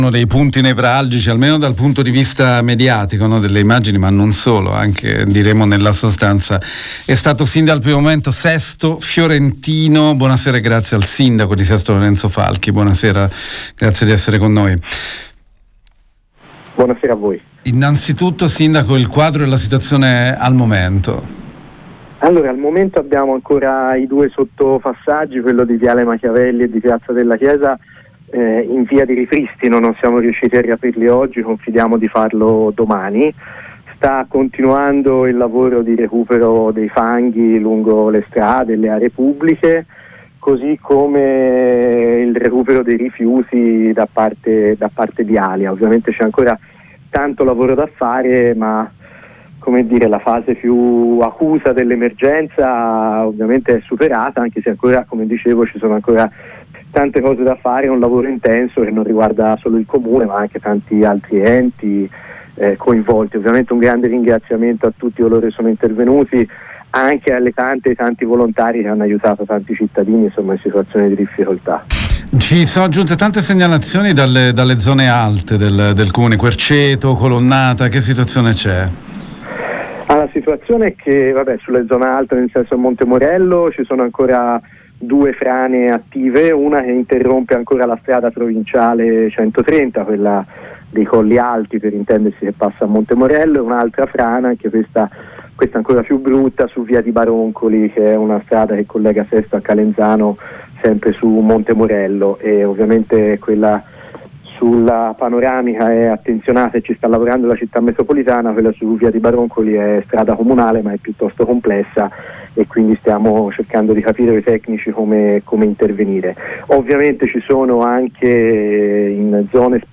SestoIntervista con il sindaco di Sesto Fiorentino Lorenzo Falchi che fa il punto sui danni  causati dal maltempo del fine settimana